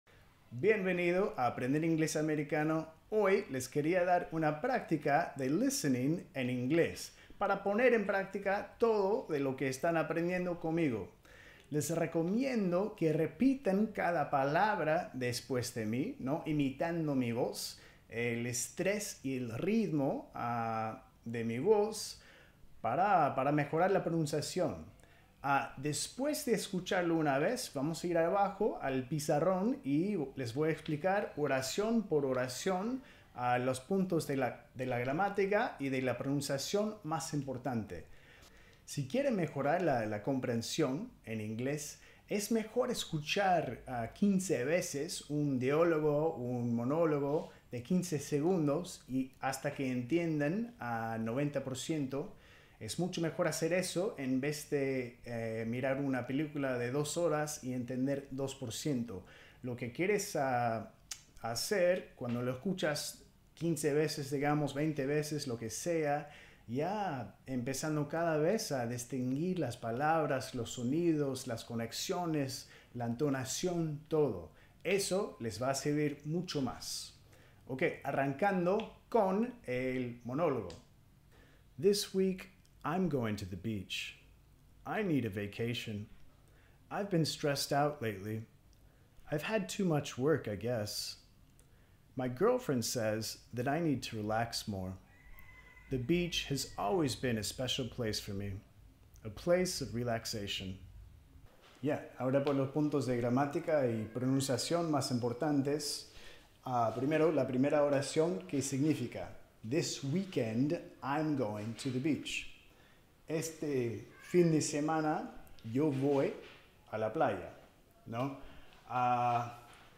Práctica de listening en inglés americano | Entrena tu oído